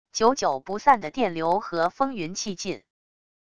久久不散的电流和风云气劲wav音频